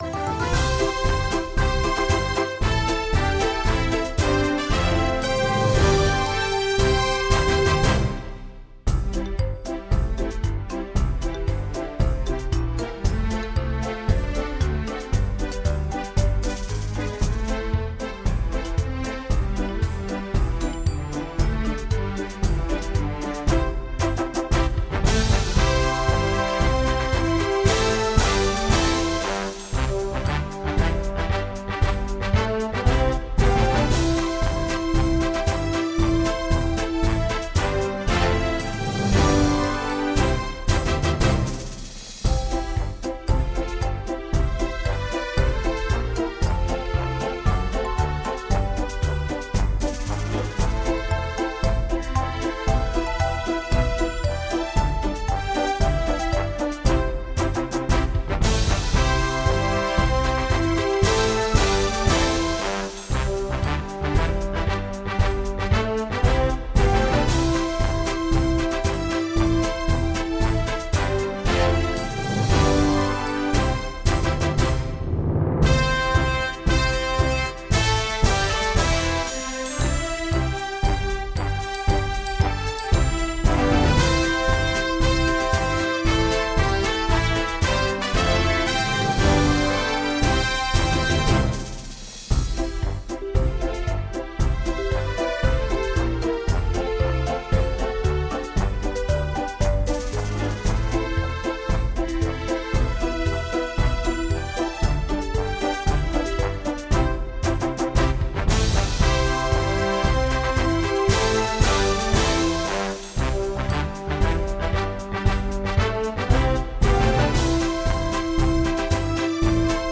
——献给考生的高考歌
乐曲为2／4拍、大调曲式，节奏分明，富有朝气。全曲结构规整，分二个乐段，第一段平稳、刚健，第二段高亢、嘹亮。